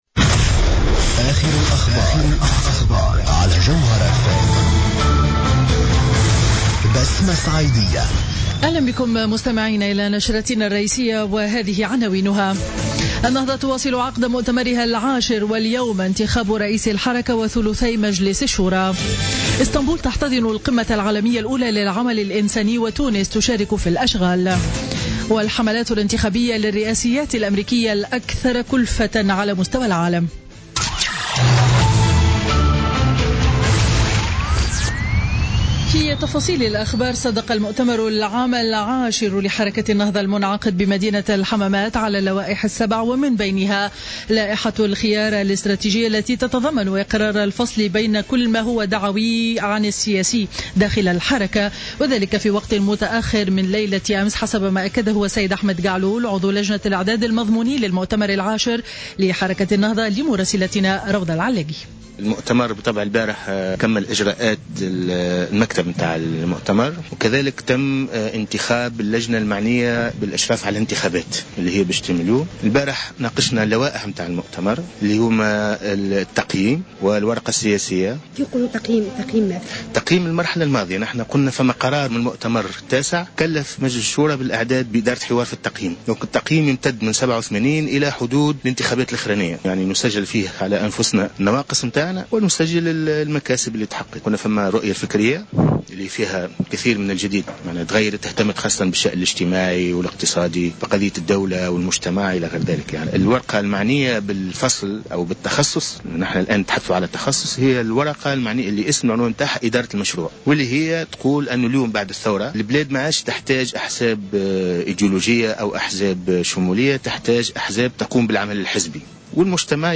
نشرة أخبار منتصف النهار ليوم الأحد 22 ماي 2016